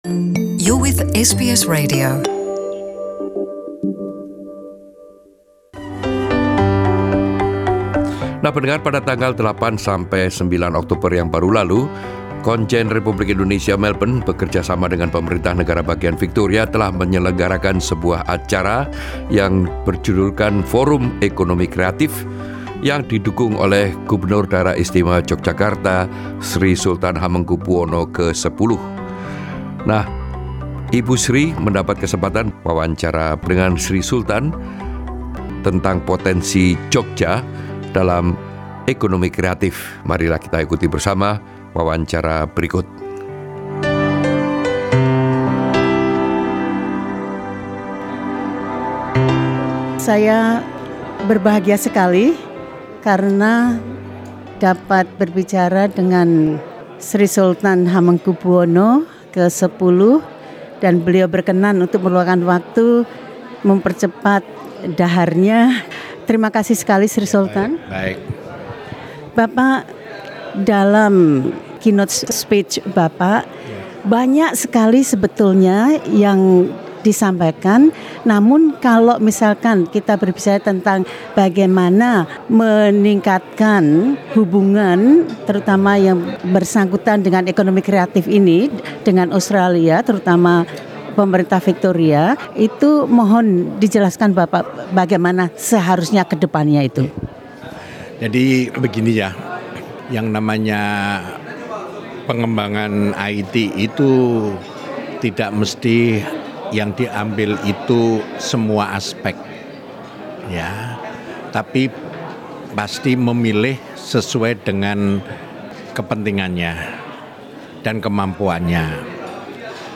Sri Sultan Hamengku Buwono X berbicara tentang memperkuat hubungan khusus ini.